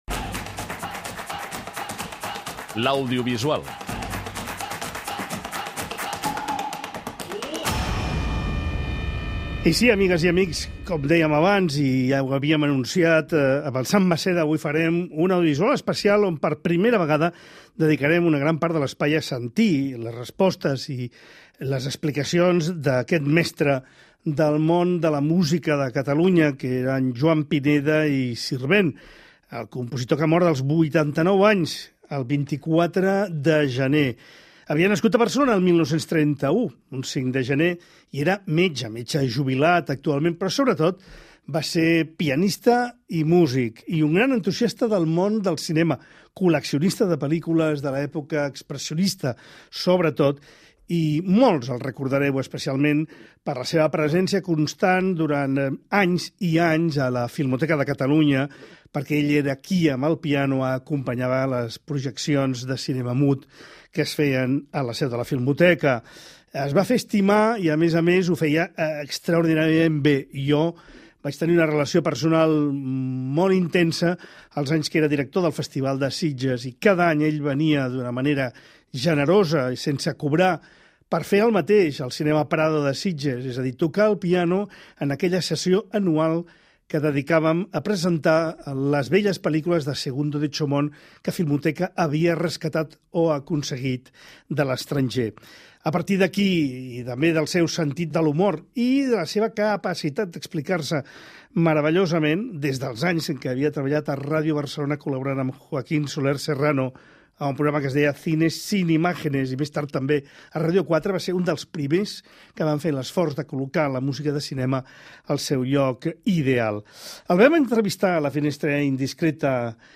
Recuperem una entrevista i tamb� podem sentir algunes de les bandes sonores m�s destacades.